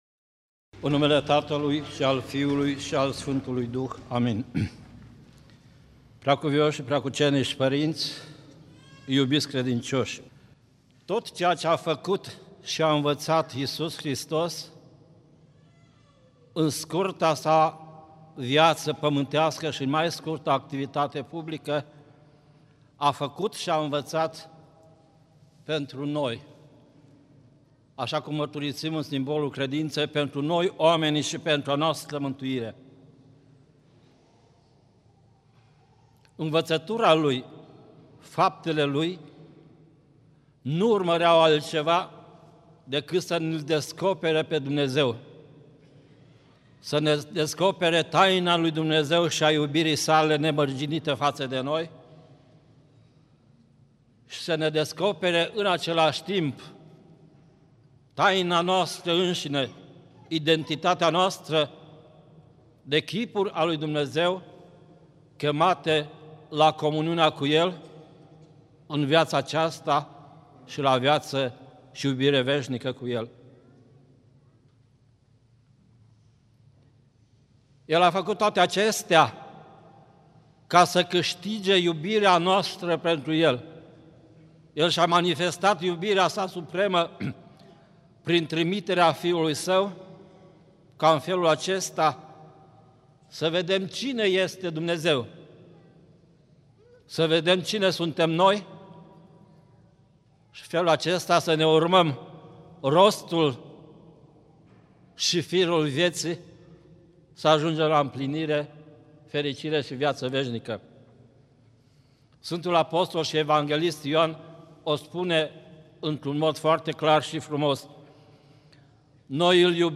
Predică la Duminica a 18-a după Rusalii
rostit în Duminica a 18-a după Rusalii (Pescuirea minunată), la Catedrala Mitropolitană din